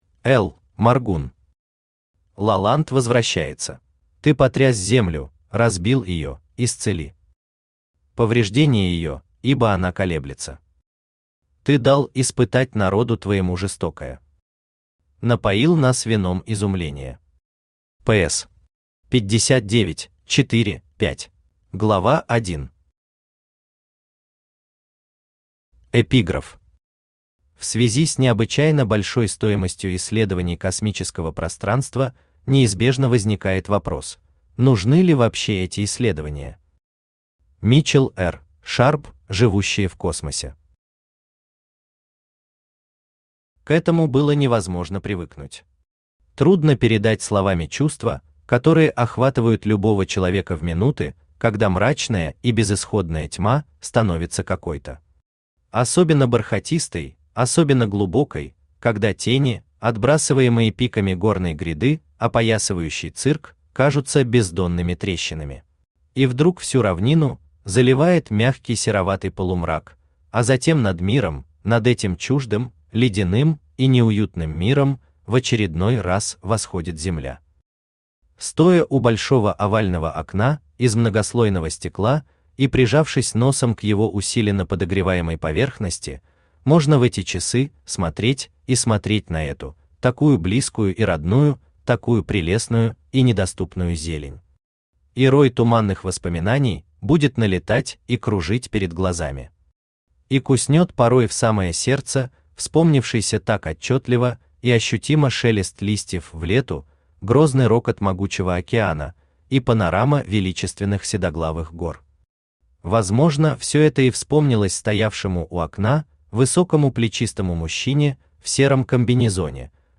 Aудиокнига «Лаланд» возвращается Автор Л. Моргун Читает аудиокнигу Авточтец ЛитРес.